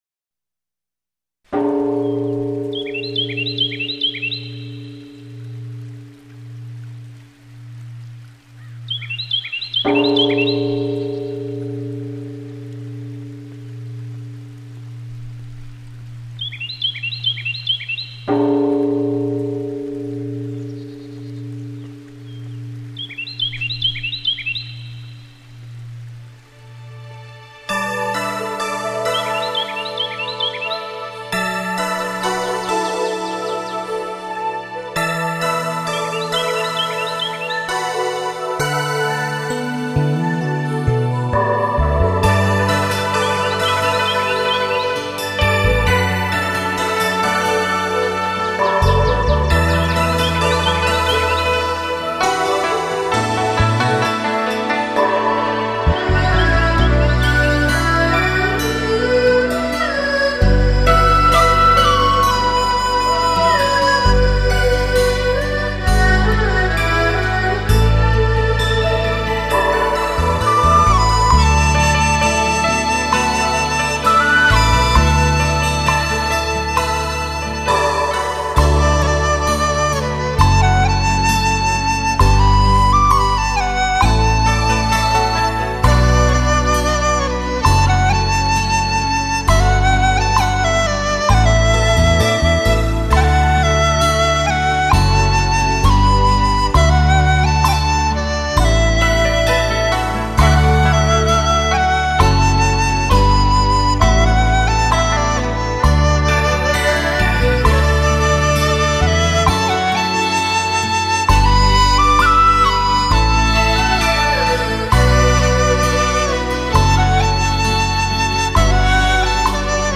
东方音乐
笃定强烈的节奏，彷佛五岳三江般的动魄人心；
悠扬的国乐，就像是环绕在江岳之上的霭霭云雾，撩人心绪。